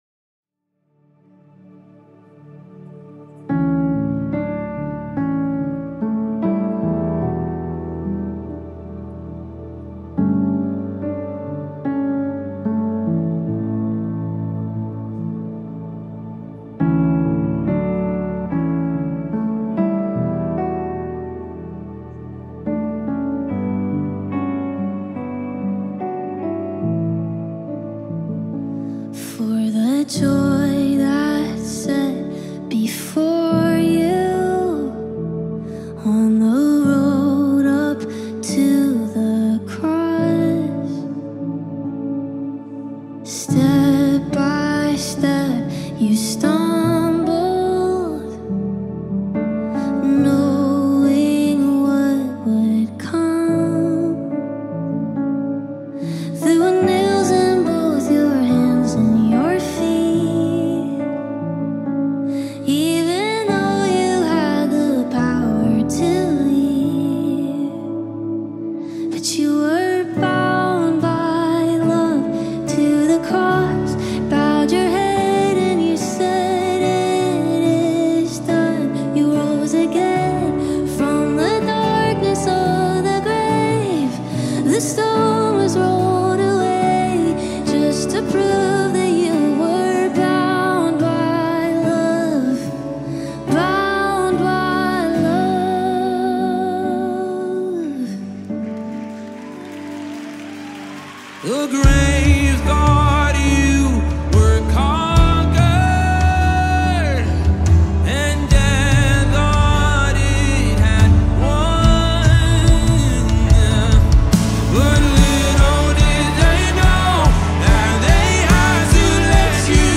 2026 single